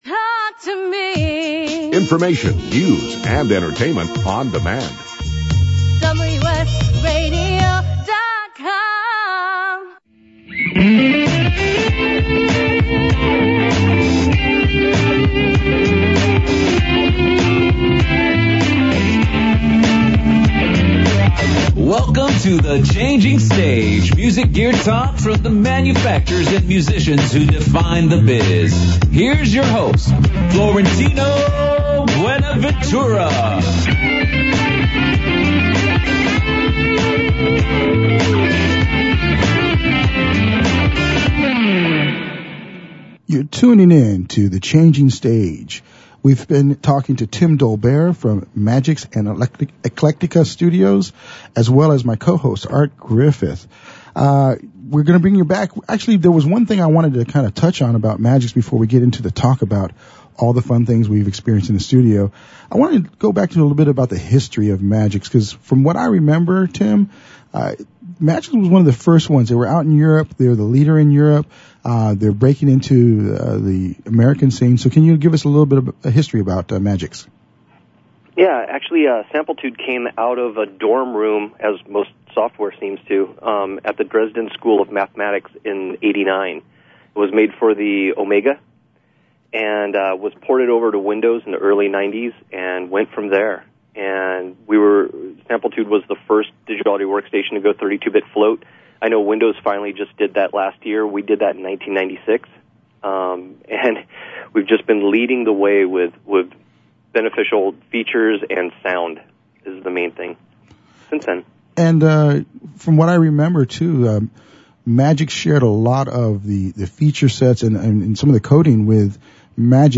Radio interviews: